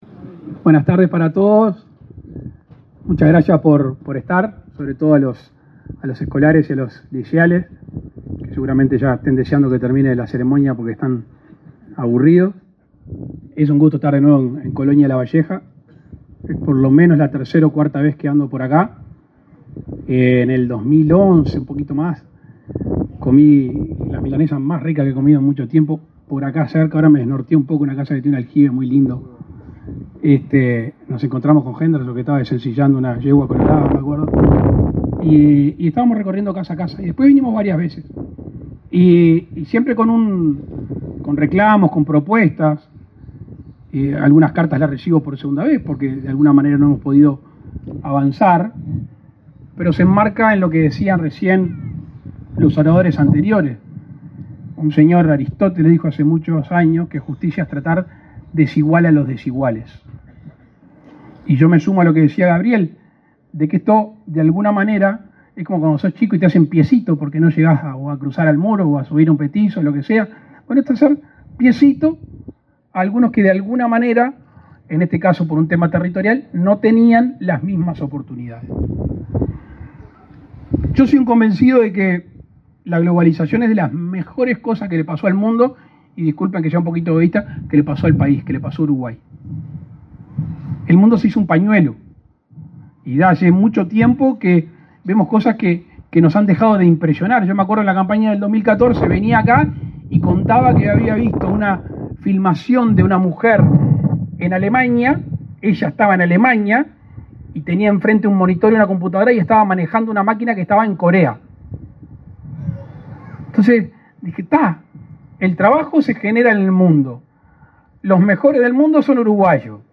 Palabras del presidente de la República, Luis Lacalle Pou
Palabras del presidente de la República, Luis Lacalle Pou 04/10/2023 Compartir Facebook X Copiar enlace WhatsApp LinkedIn El presidente de la República, Luis Lacalle Pou, participó este 4 de octubre en la celebración correspondiente a que 100% de los centros educativos públicos uruguayos cuentan con conectividad a internet con banda ancha. La ceremonia se concretó en Pueblo Migliaro, Salto.